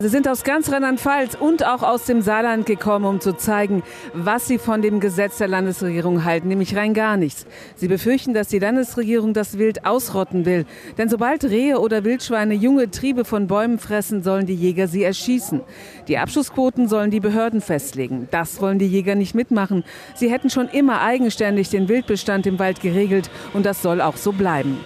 Tausende Jägerinnen und Jäger haben in Mainz gegen das geplante neue Jagdgesetz demonstriert. Mit Jagdhörnern und Transparenten zogen sie durch die Innenstadt.
Jäger demonstrieren mit Jagdhörner in Mainz gegen das neue Jagdgesetz.